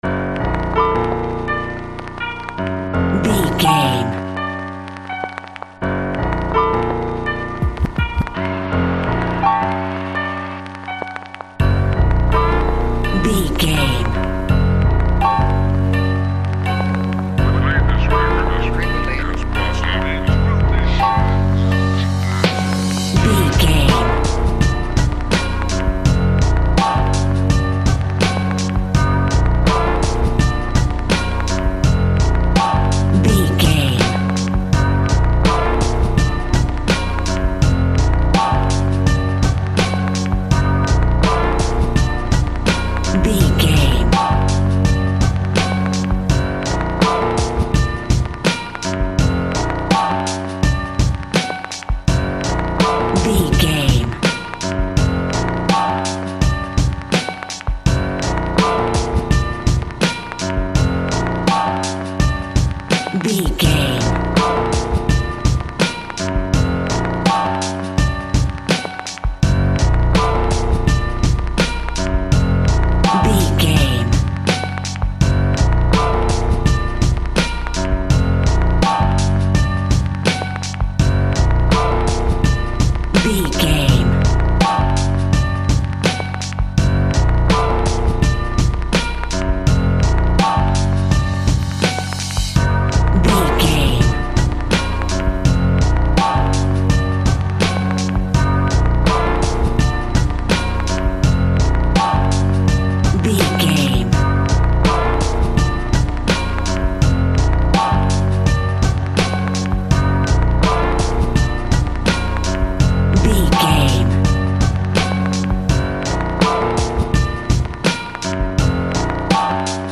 Aeolian/Minor
B♭
chilled
laid back
groove
hip hop drums
hip hop synths
piano
hip hop pads